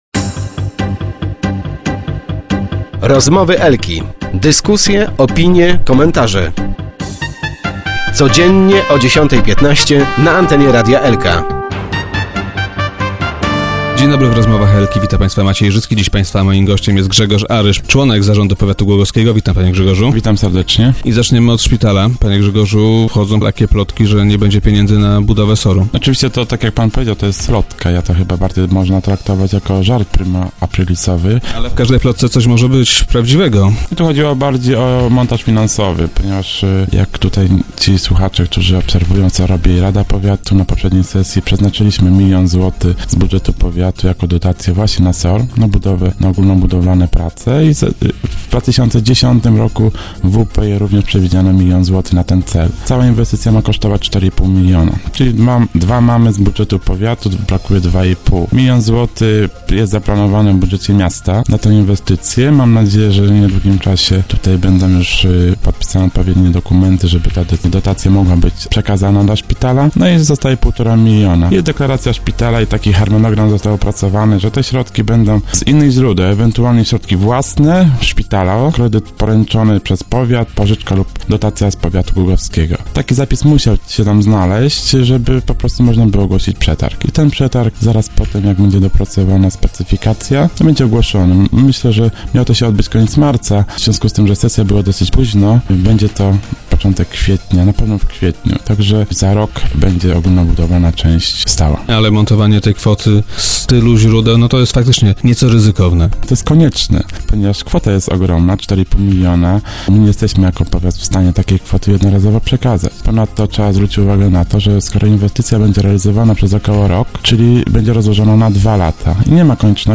- Nie powinno być problemów z pieniędzmi na ten cel - rozwiewa te wątpliwości Grzegorz Aryż, członek zarządu powiatu głogowskiego, który był dziś gościem Rozmów Elki.